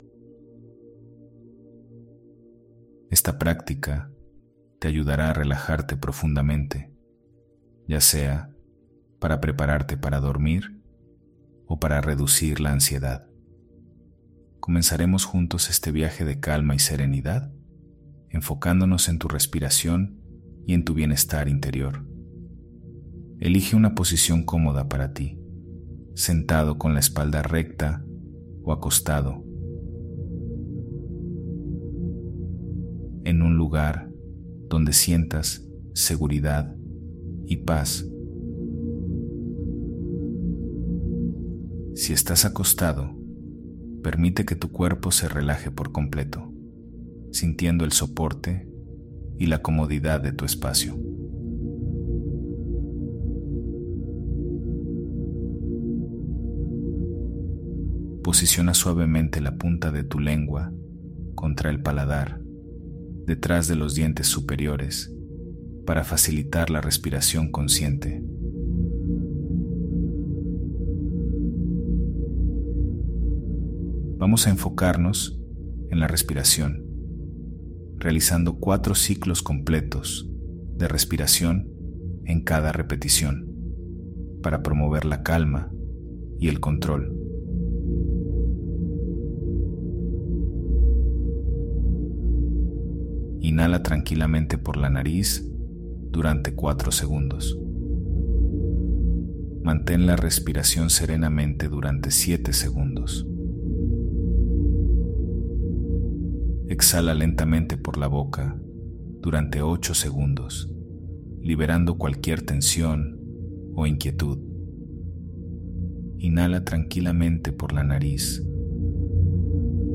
X Meditation BLS binaural beats Space 0:00 00:00 Respiración 4-7-8 Observación consciente Deja una primera calificación Añadir a favoritos Share Haz clic en el botón PLAY para comenzar tu práctica. Los tres beneficios principales de realizar este ejercicio son: Calma : La meditación guía hacia una respiración profunda y controlada, lo cual es fundamental para calmar la mente y el cuerpo.